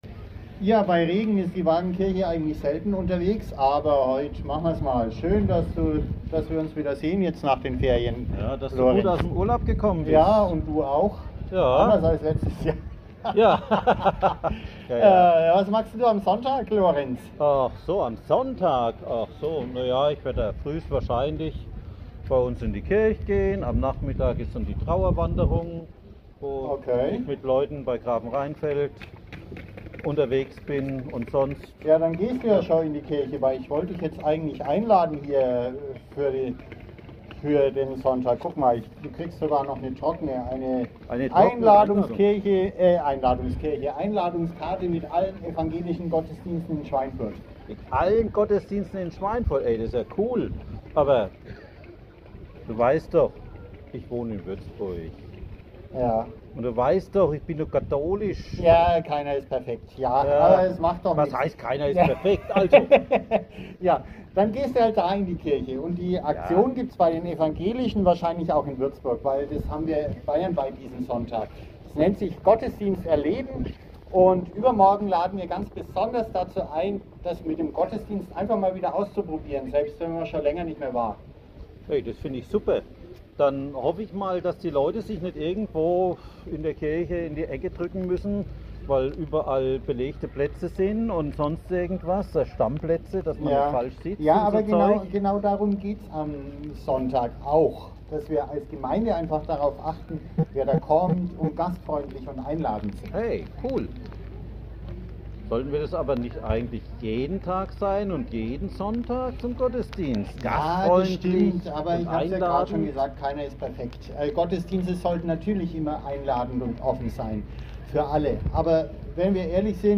Kurze Impulse zum Nachdenken fürs Wochenende.